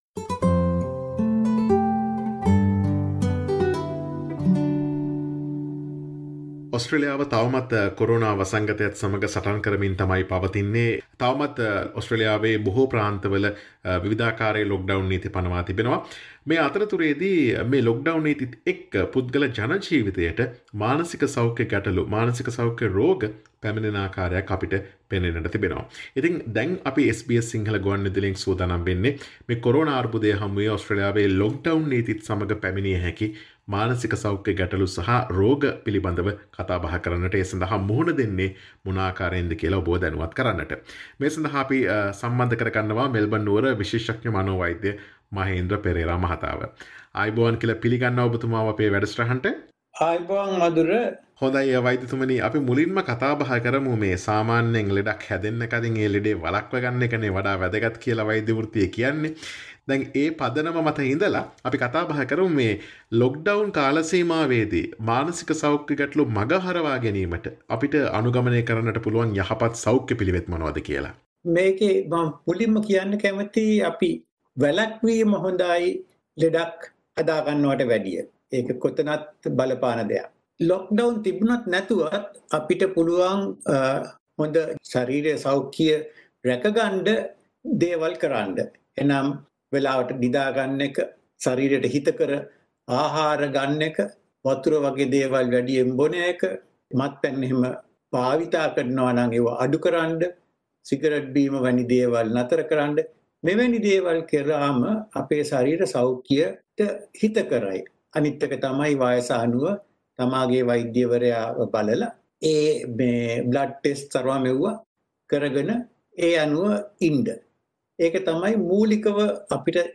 SBS සිංහල ගුවන් විදුලිය සිදුකළ සාකච්ඡාව.